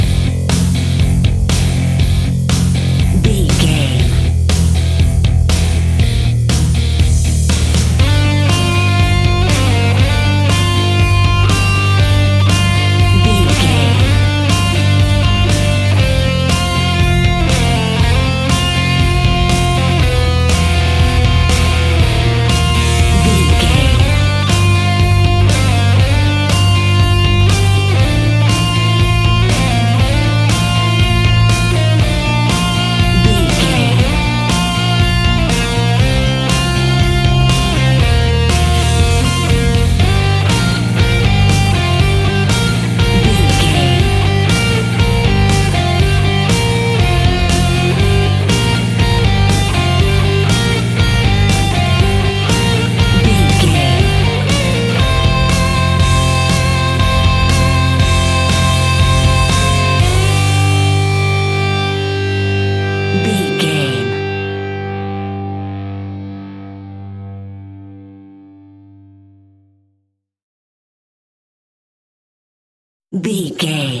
Epic / Action
Fast paced
Aeolian/Minor
hard rock
blues rock
rock instrumentals
rock guitars
Rock Drums
heavy drums
distorted guitars
hammond organ